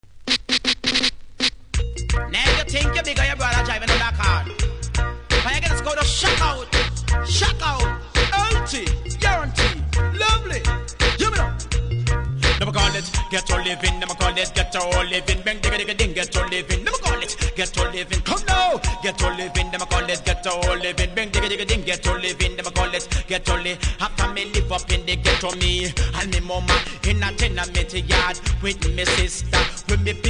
REGGAE 80'S